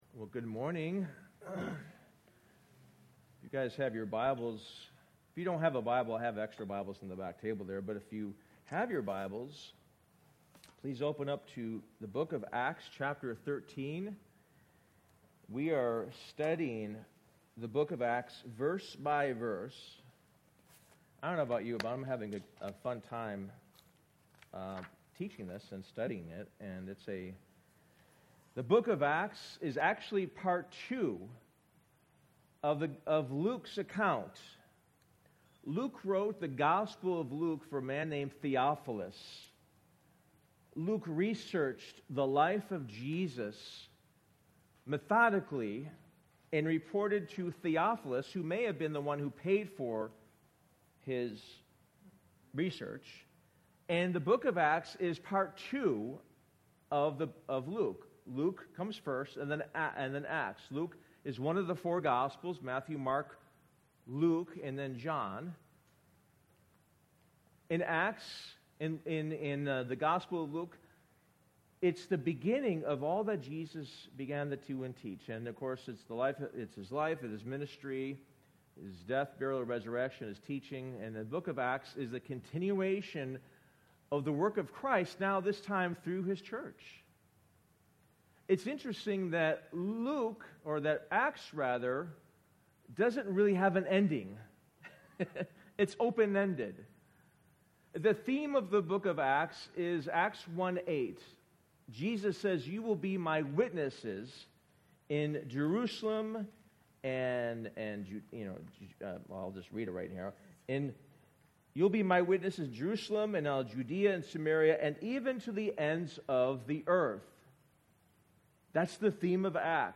Sermon: The Gospel Goes Out to the World